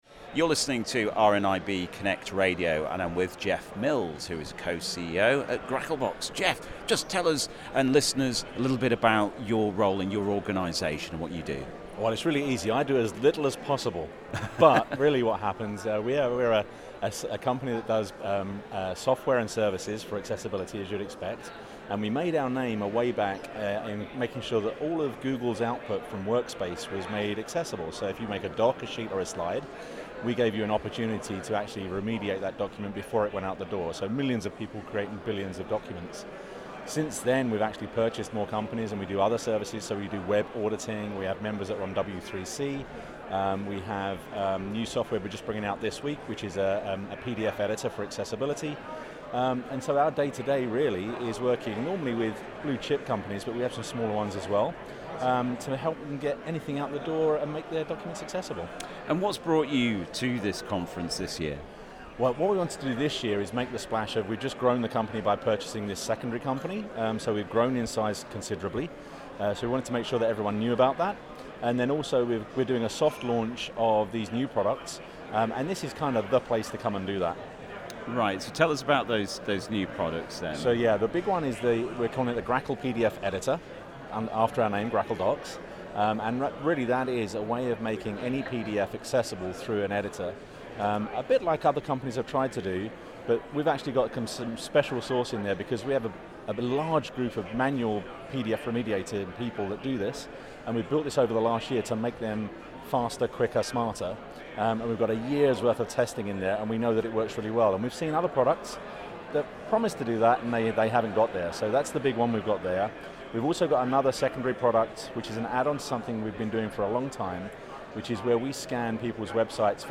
RNIB Connect CSUN 2024 - Grackle Docs Season 2 Episode 412